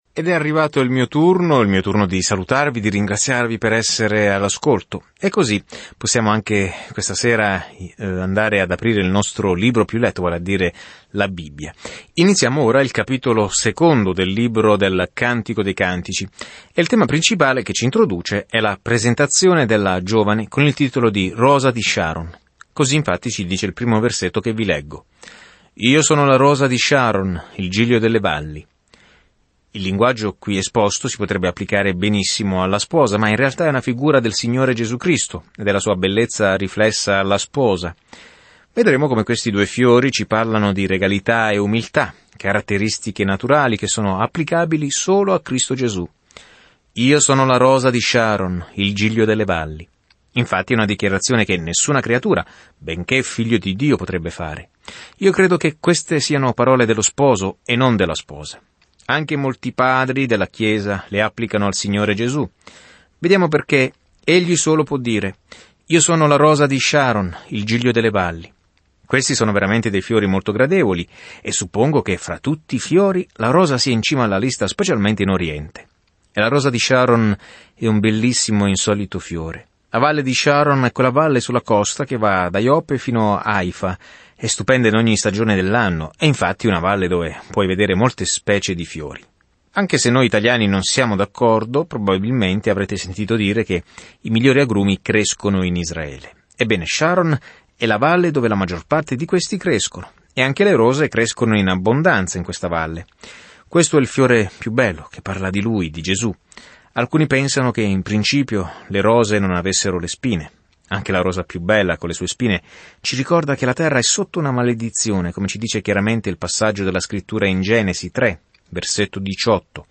Scrittura Cantico dei Cantici 2:1-8 Giorno 3 Inizia questo Piano Giorno 5 Riguardo questo Piano I Cantici dei Cantici è una piccola canzone d'amore che celebra l'amore, il desiderio e il matrimonio con un ampio paragone con il modo in cui Dio ci ha amati per la prima volta. Viaggia ogni giorno attraverso il Cantico dei Cantici mentre ascolti lo studio audio e leggi versetti selezionati della parola di Dio.